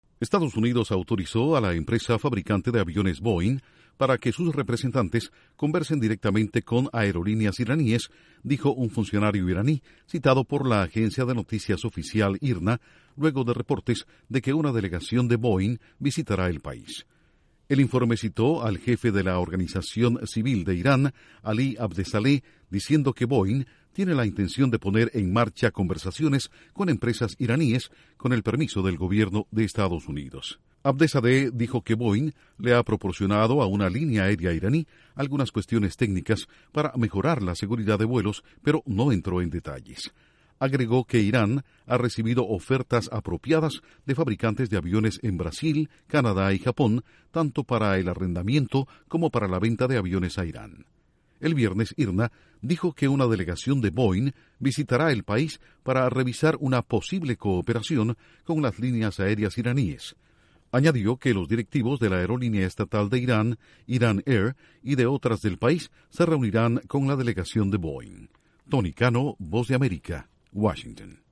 El fabricante de aviones estadounidense Boeing ingresaría al mercado iraní según informes de la prensa oficial de Irán. Informa desde la Voz de América